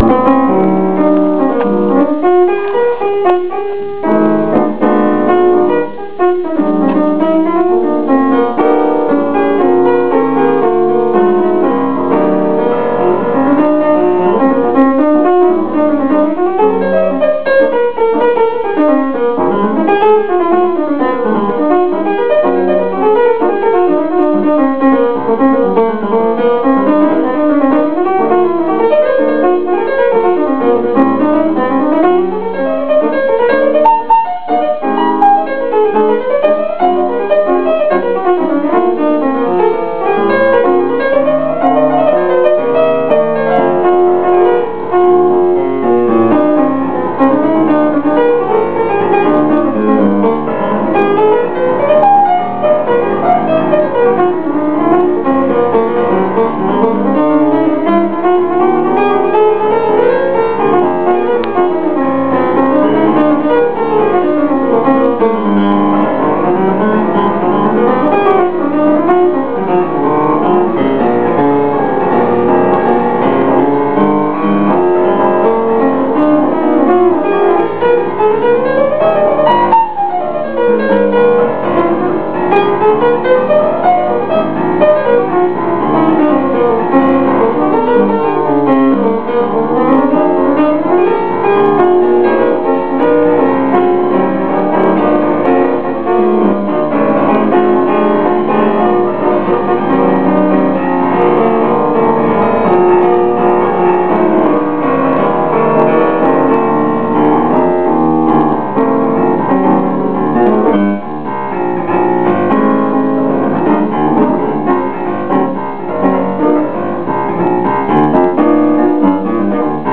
스탠다드 재즈곡인
쇼케이스때 친 곡인데